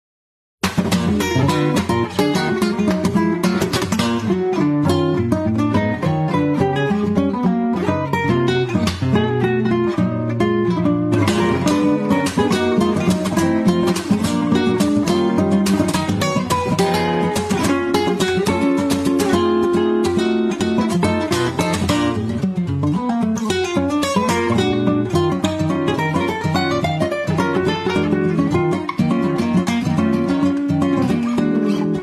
played with our virtual guitar